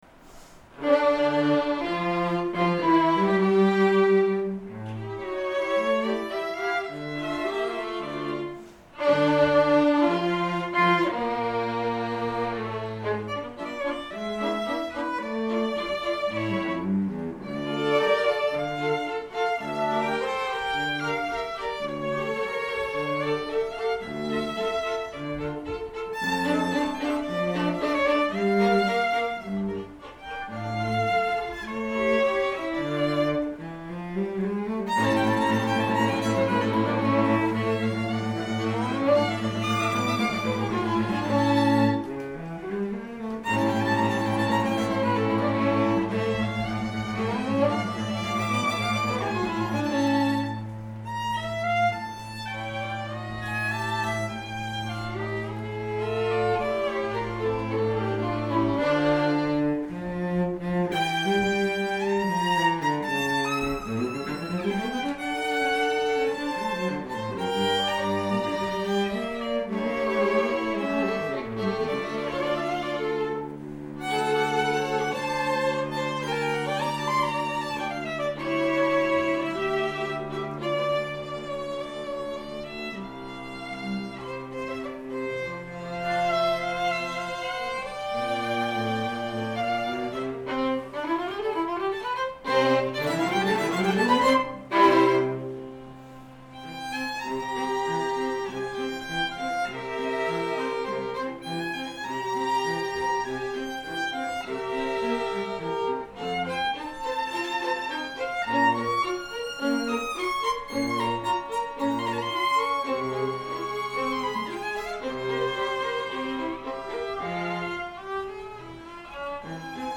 2:00 PM on July 28, 2012, South Bay Church
Chamber Groups
Arriaga Quartet no.1
Allegro